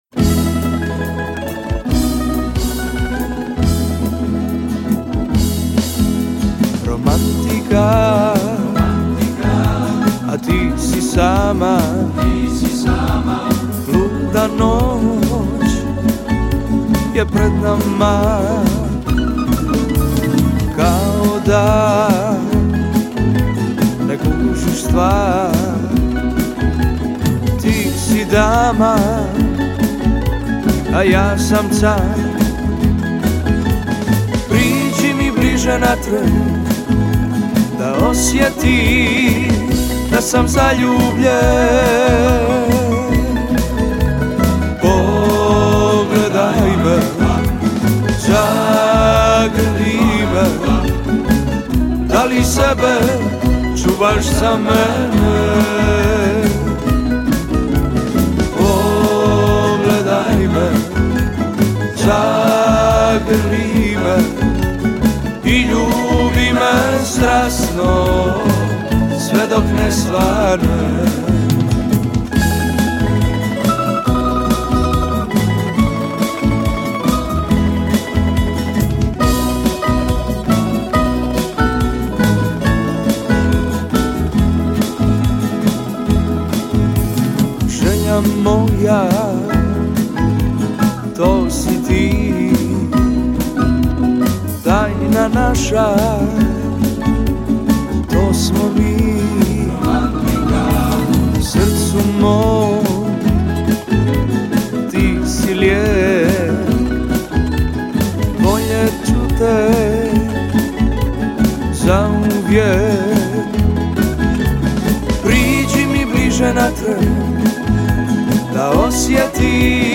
Općina Kaptol ovim putem poziva sve ljubitelje dobre tamburaške glazbe da posjete Kaptol 03. svibnja 2013. godine.
Pobjednička pjesma prošlogodišnjeg festivala: